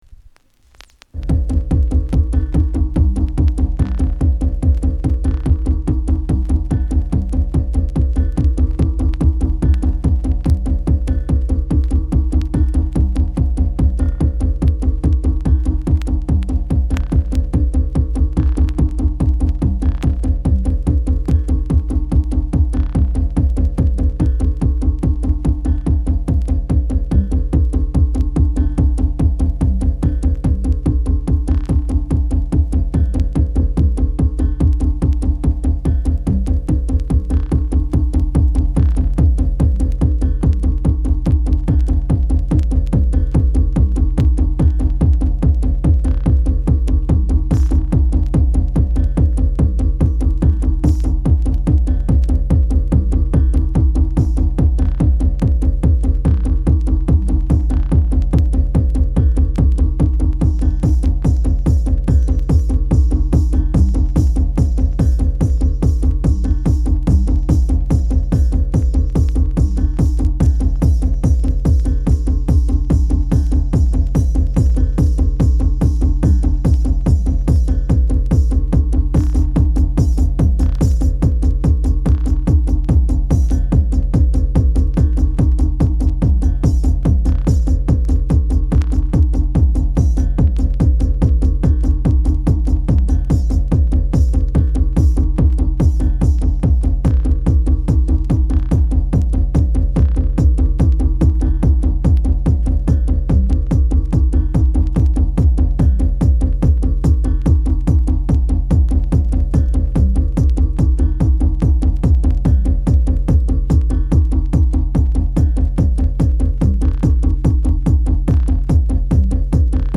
Techno , Abstract , Trip Hop